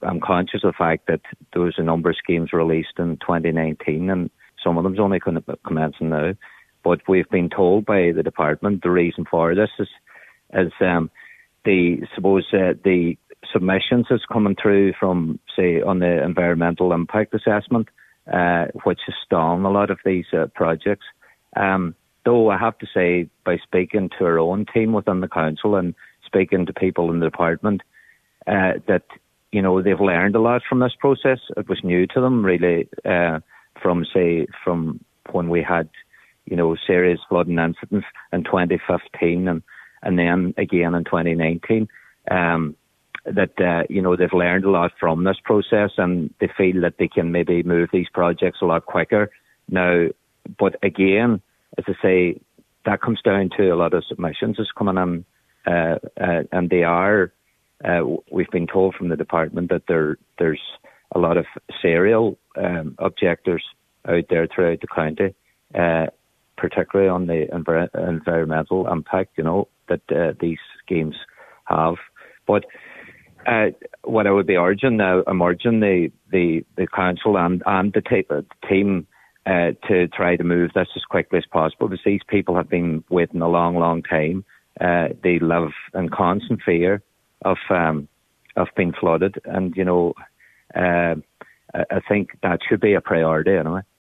Councillor Noel Jordan says while the announcement of the schemes is to be welcomed, he is mindful that many similar initiatives have taken a number of years to come to fruition.